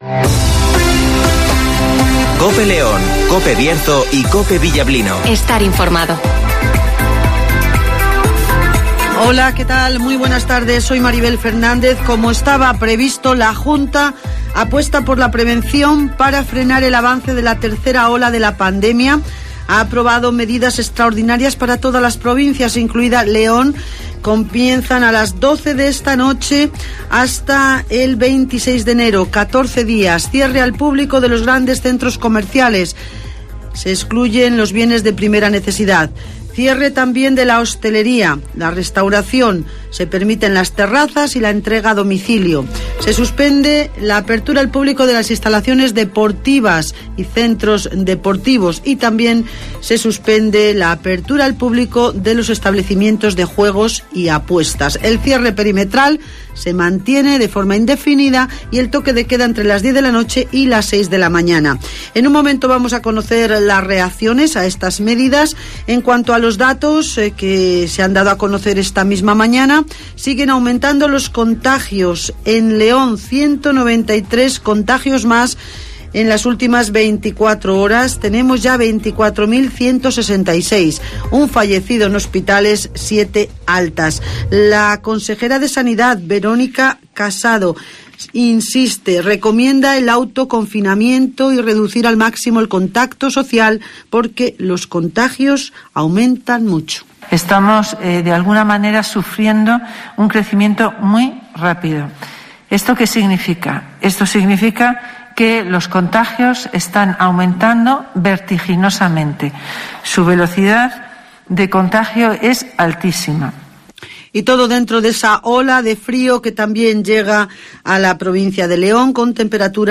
AUDIO: Repaso a la actualidad informativa de la provincia de León. Escucha aquí las noticias con las voces de los protagonistas.